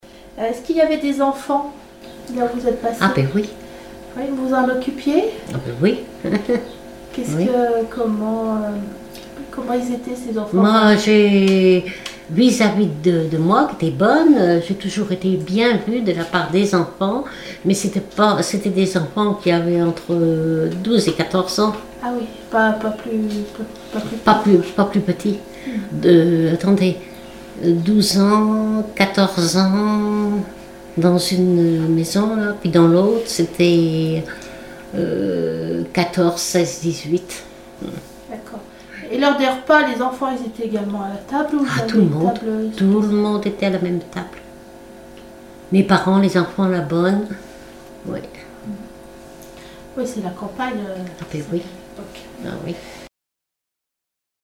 Témoignages sur la vie d'une servante et des noces